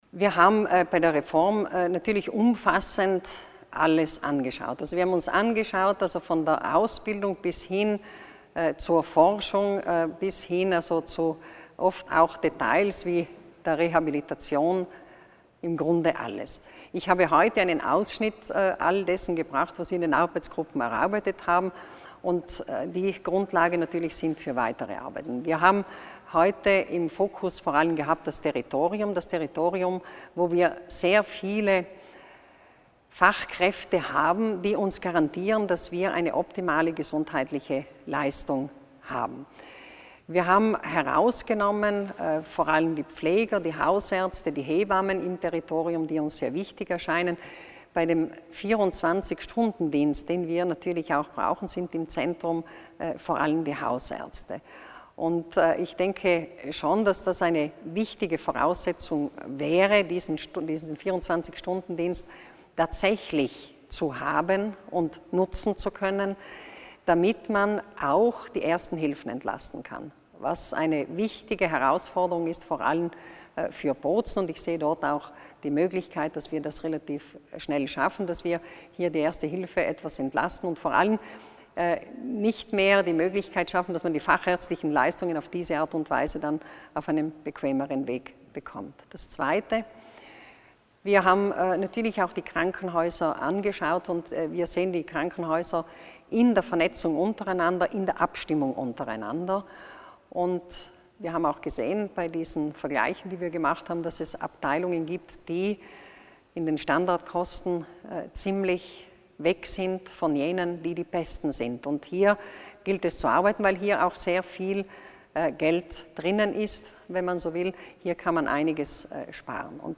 Die Landesrätin für Gesundheit und Soziales, Martha Stocker, hat heute (26. September) bei einer Pressekonferenz im Palais Widmann die Entwicklungsleitlinien der Gesundheitsversorgung in Südtirol den Medien vorgestellt.